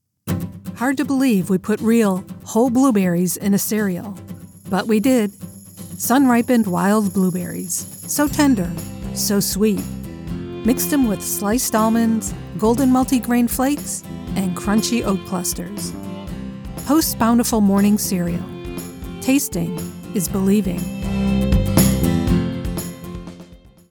Professional Female Voice Over Talent
Commercial Sample
Let’s work together to bring your words to life with my conversational, authoritative and articulate voice.